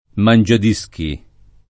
[ man J ad &S ki ]